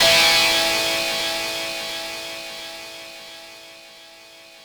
ChordA7.wav